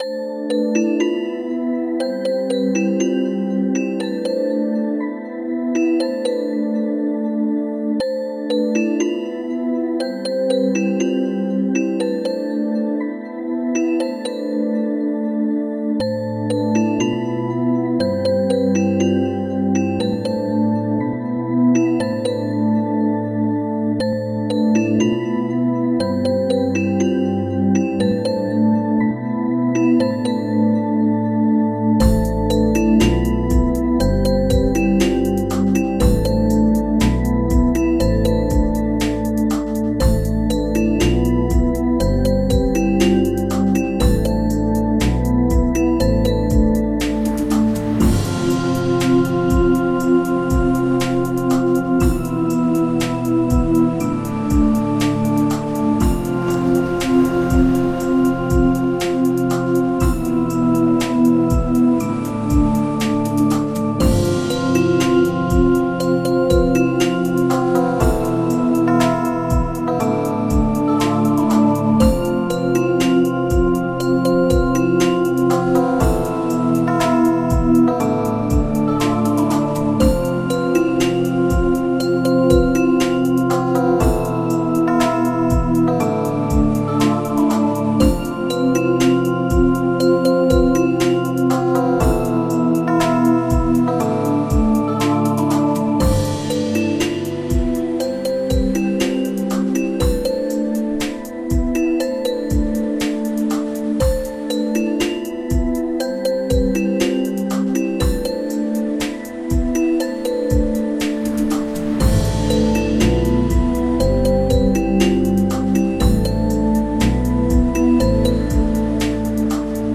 Synth Version